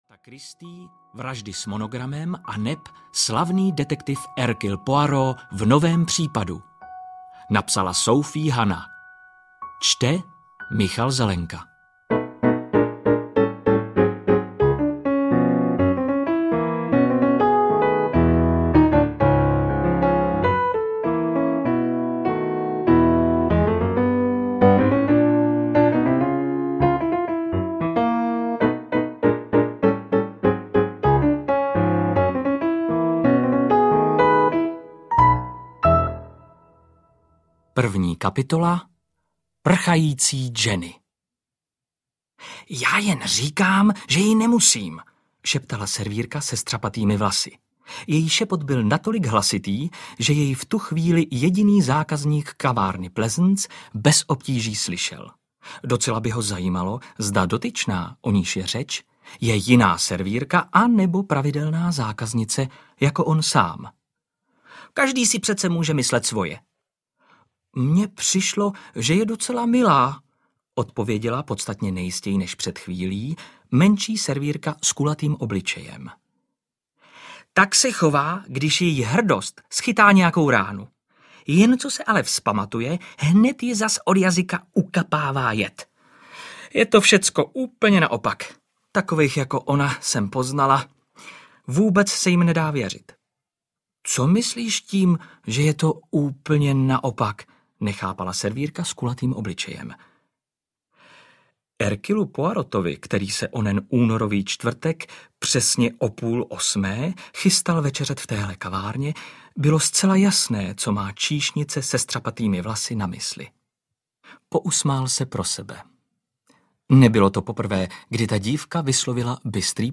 Vraždy s monogramem audiokniha
Ukázka z knihy